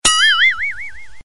Catégorie Drôle